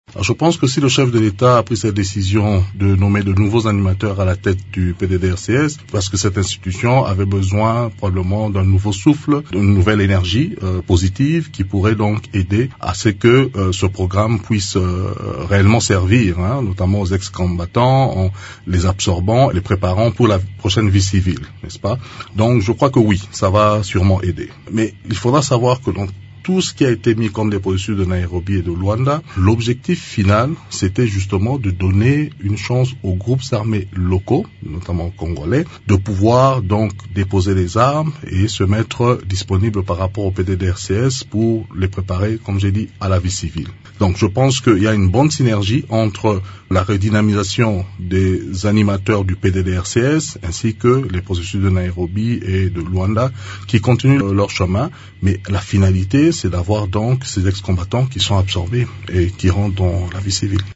Dans une interview exclusive à Radio Okapi, Serge Tshibangu souligne que le chef de l’Etat a nommé ces nouveaux animateurs pour redynamiser la coordination du Programme de désarmement, démobilisation, relèvement communautaire et stabilisation (P-DDRCS).